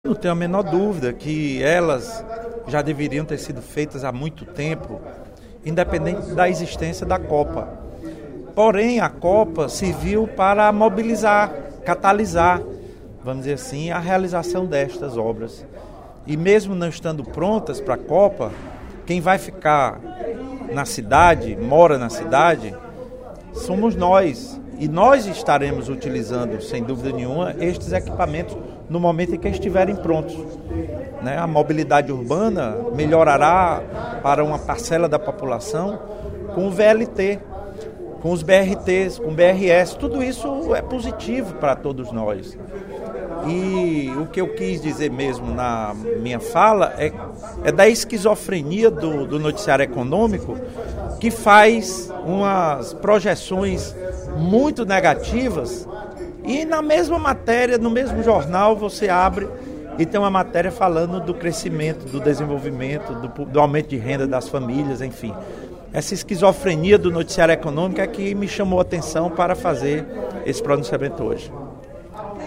Durante o primeiro expediente da sessão plenária desta terça-feira (03/06), deputado Lula Morais (PCdoB) chamou de “esquizofrenia” o noticiário econômico no Brasil, que faz projeções negativas e que, na mesma matéria, no mesmo jornal, fala do crescimento do País e do aumento de renda das famílias.